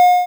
ball-hit.wav